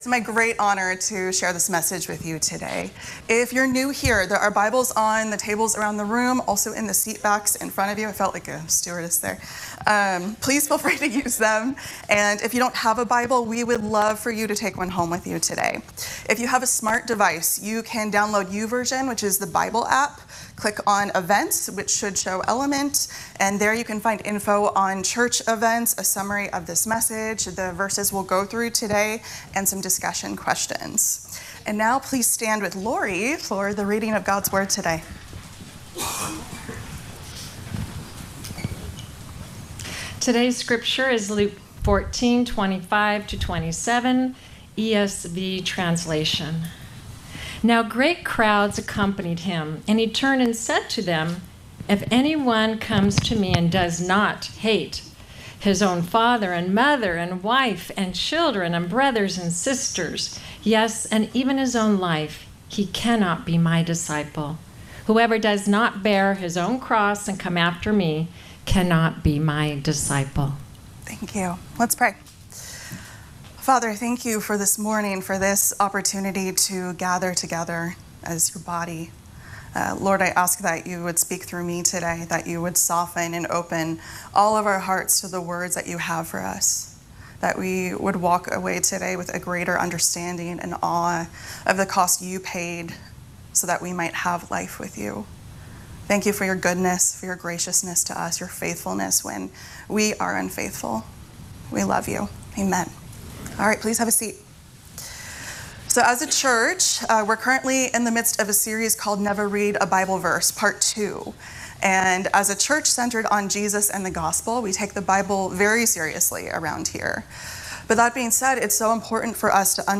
Message Archive - Element Christian Church